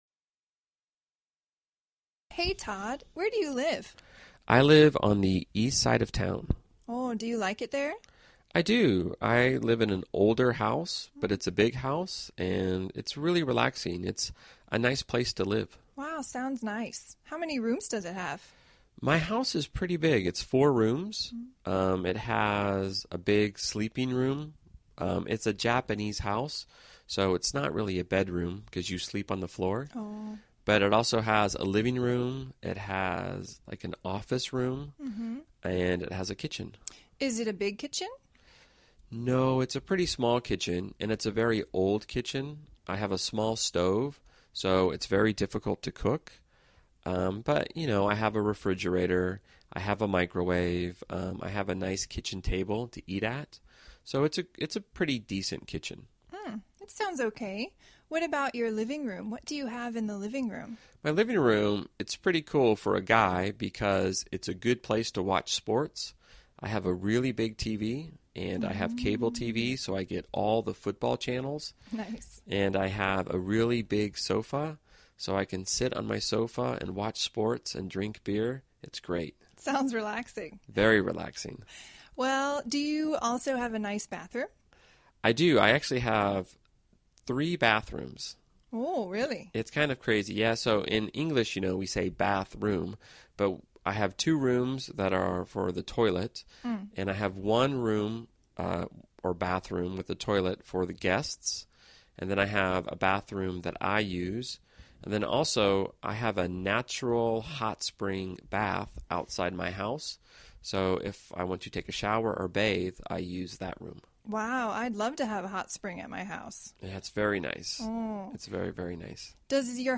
实战口语情景对话 第1146期:The Home of ELLLO ELLO网总部